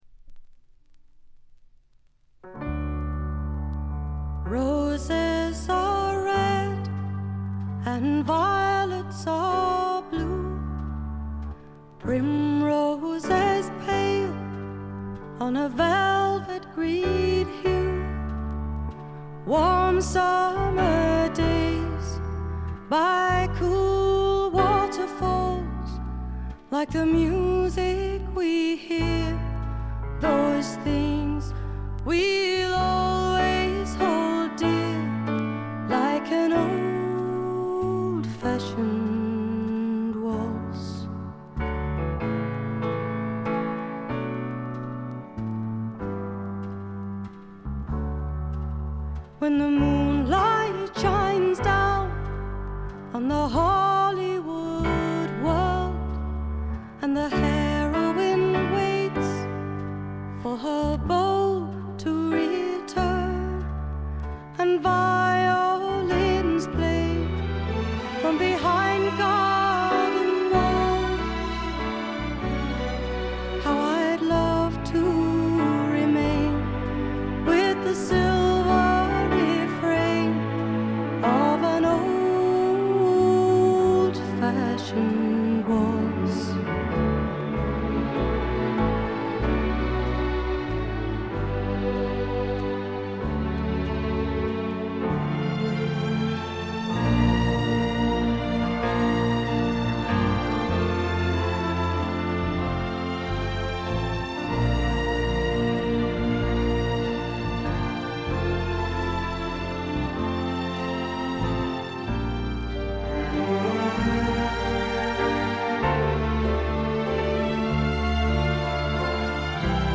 ところどころでチリプチがやや目立ちますが凶悪なノイズはありません。
1stのようないかにもな英国フォークらしさは影を潜め、オールドタイミーなアメリカンミュージック風味が加わってきています。
試聴曲は現品からの取り込み音源です。
vocals, piano, acoustic guitar